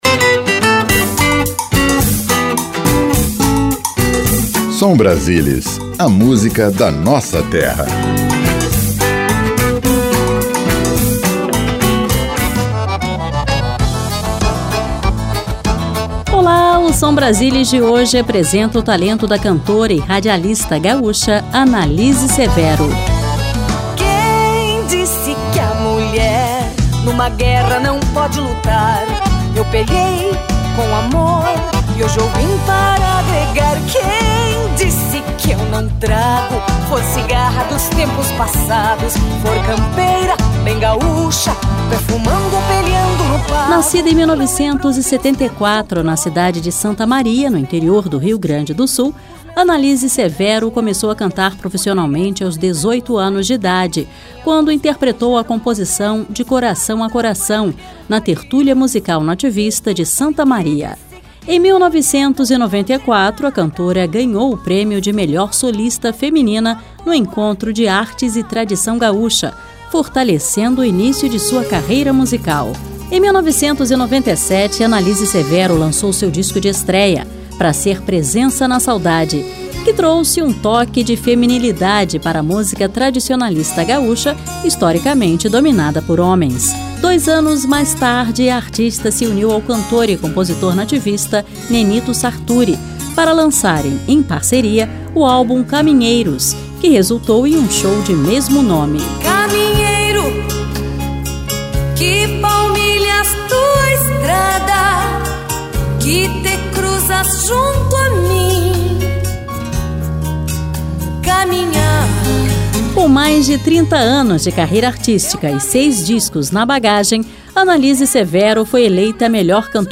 Regional